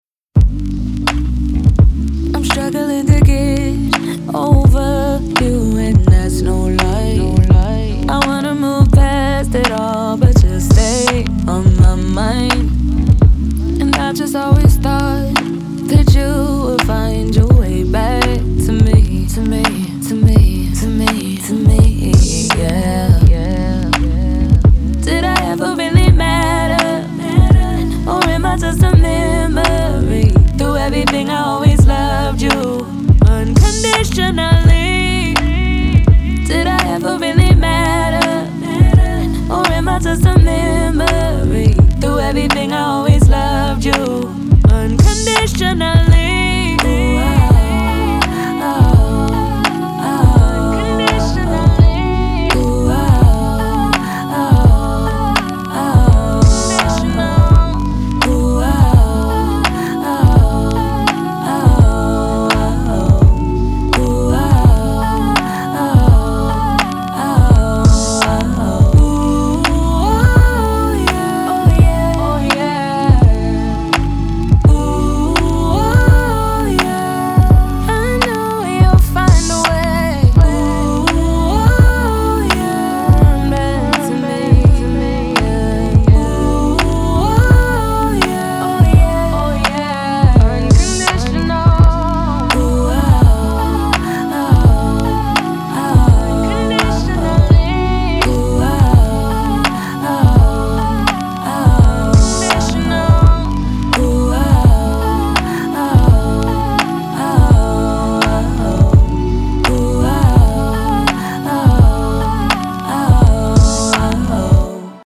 R&B, Lofi
A maj